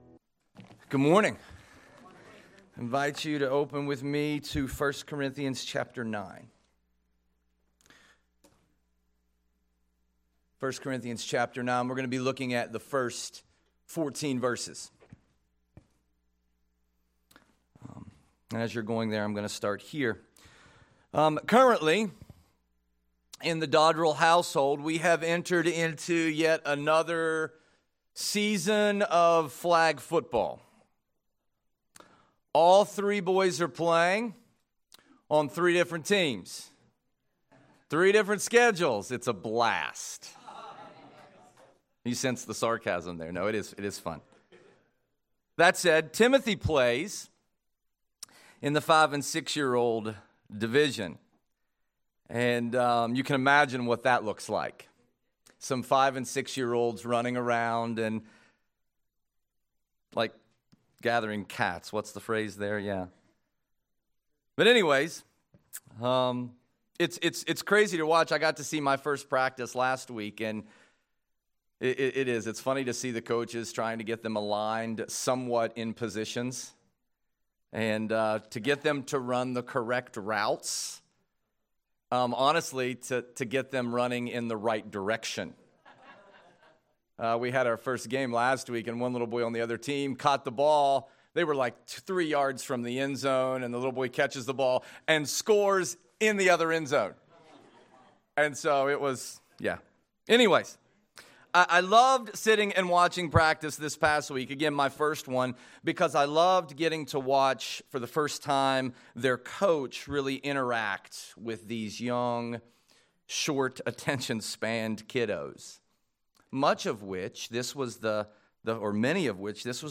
Sep 14, 2025 Leading By Example (09/14/2025) MP3 SUBSCRIBE on iTunes(Podcast) Notes Discussion Sermons in this Series 1 Corinthians 9:1-14 Loading Discusson...